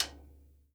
CLICKONR.1-L.wav